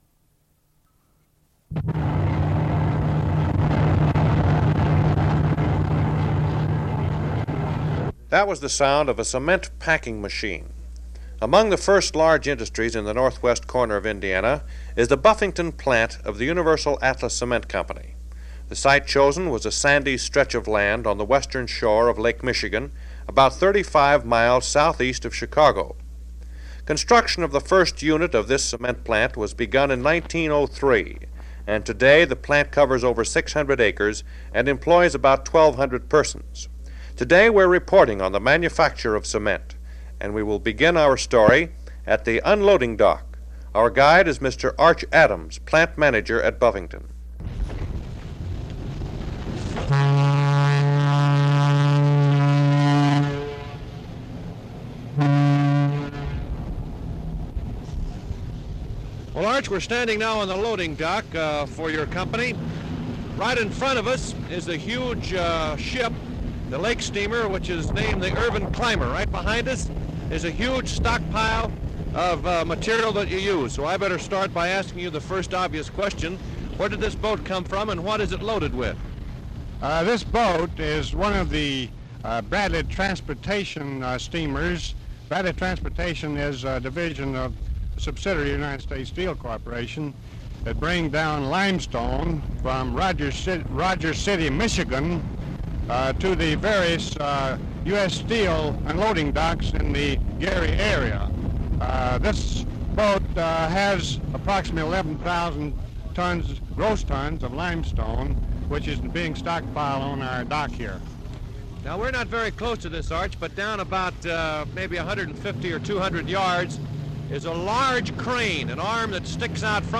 Preservation master file of digitized 7" open reel tape; tape digitized at 96 kHz/24-bit.